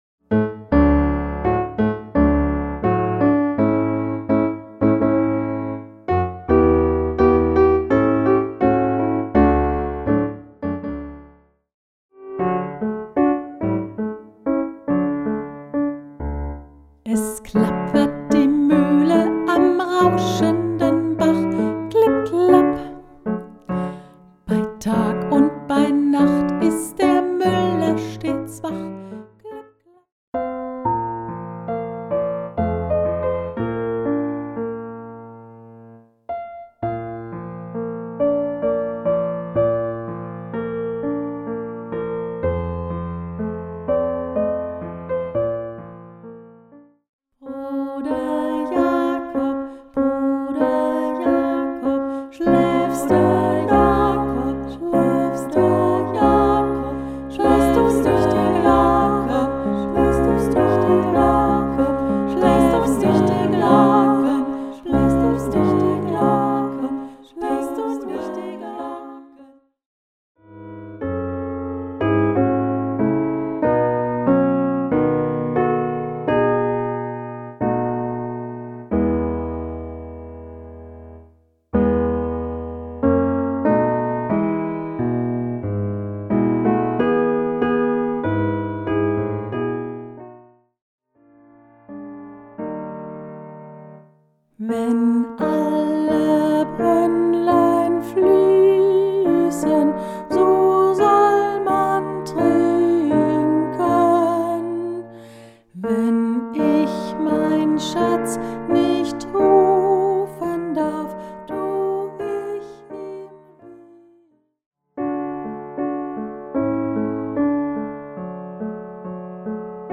Hoerprobe-CD-Bunte-Volkslieder-zum-Mitsingen.mp3